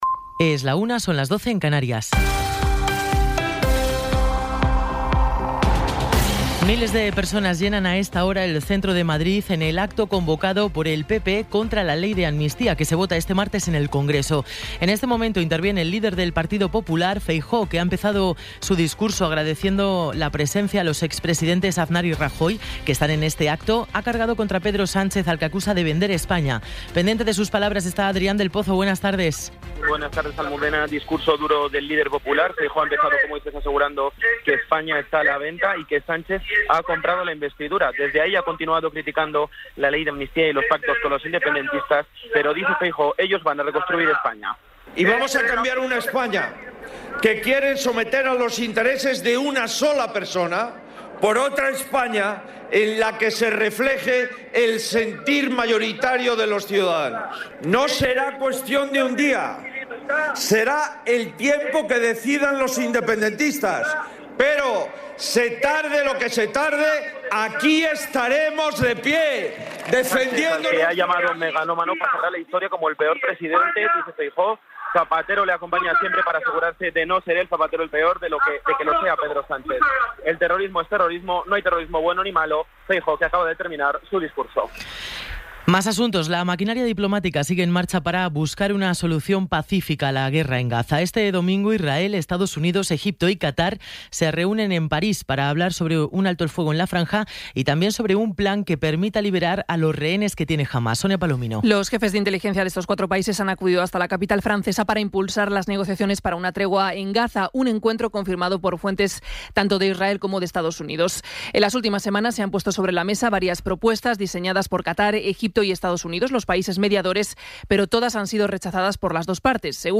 Resumen informativo con las noticias más destacadas del 28 de enero de 2024 a la una de la tarde.